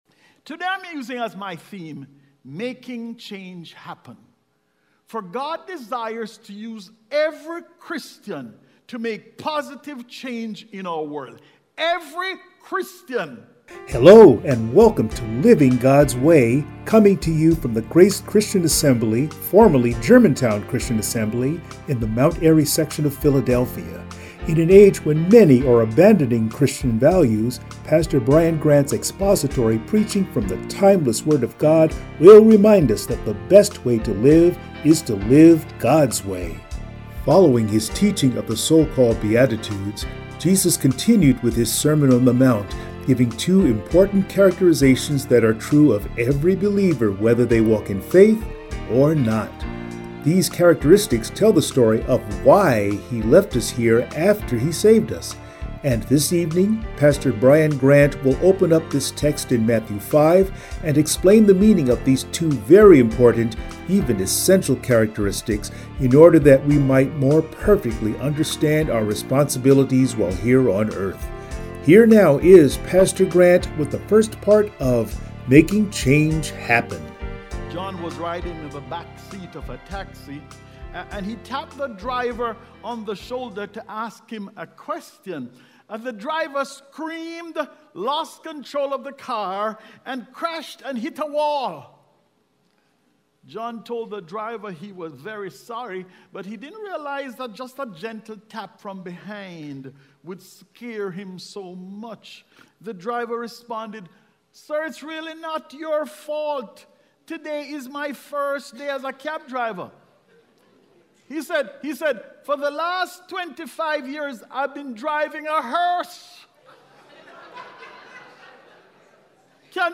Sermon
Service Type: Sunday Morning